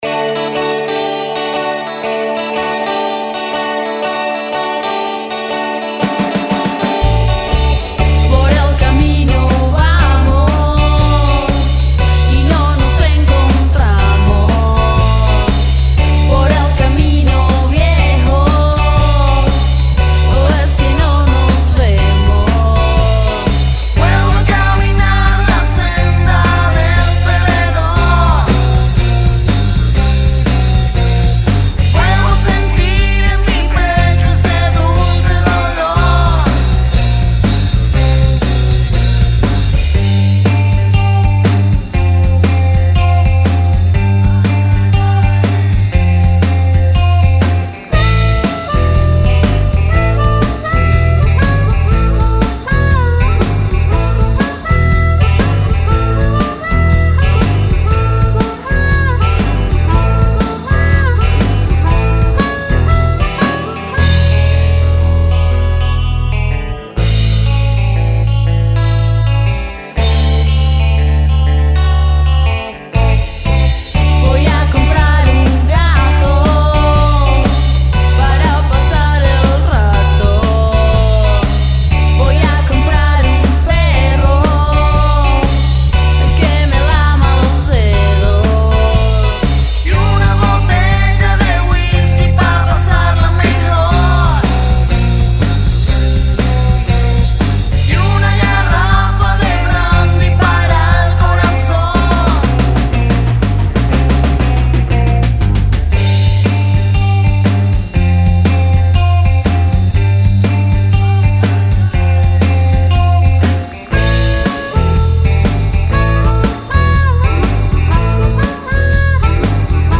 rock mestizo y paralelo
Rock Alternativo